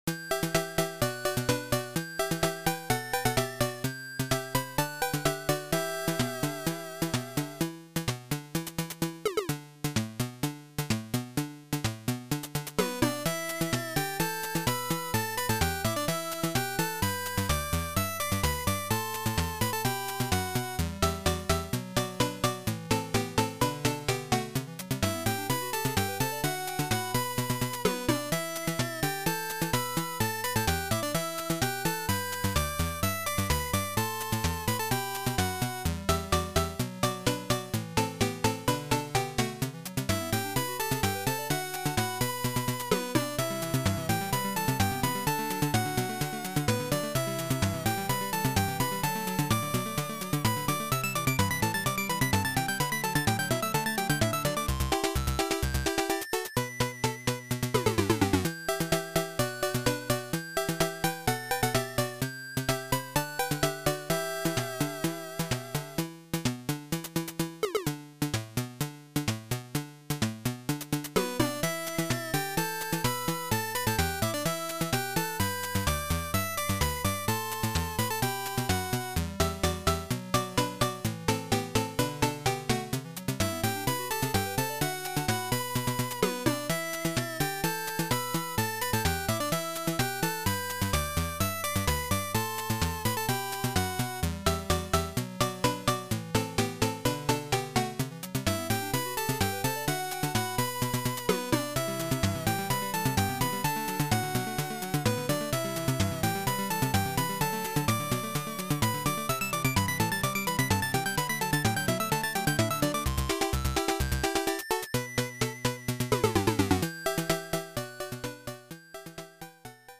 ここではMUCOM88winで作ったMUCソースとMP3化したファイルを掲載しています。